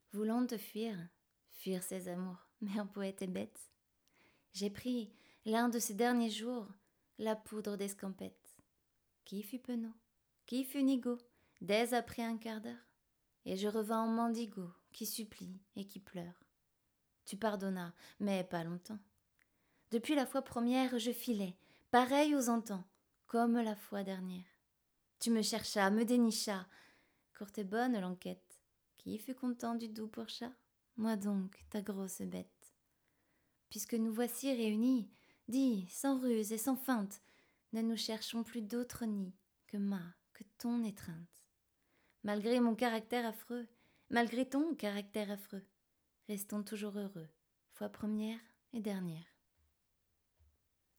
Voix off féminine francophone
Poème Verlaine, exemple de narration
Middle Aged